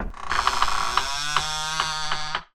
Открывание крышки гроба